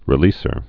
(rĭ-lēsər)